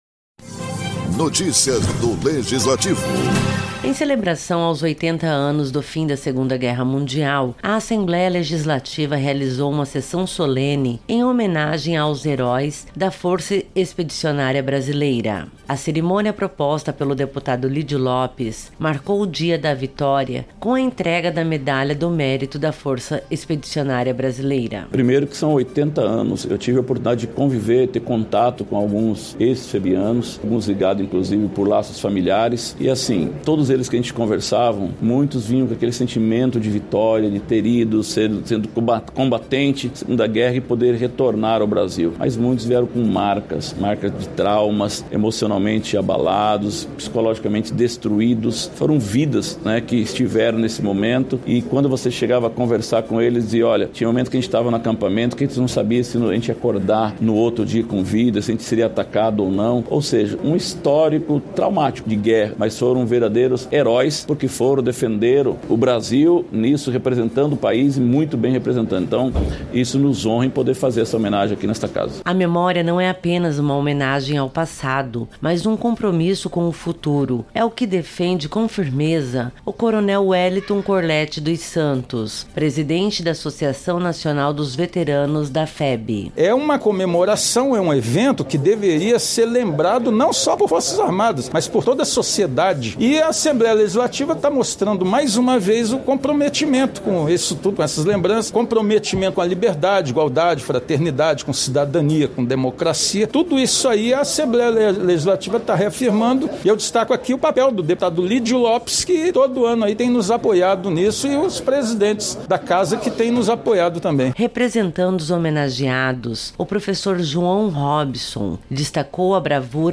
Oito décadas após o fim da Segunda Guerra Mundial, a Assembleia Legislativa de Mato Grosso do Sul realizou uma sessão solene em homenagem aos heróis da Força Expedicionária Brasileira (FEB).